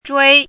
chinese-voice - 汉字语音库
zhui1.mp3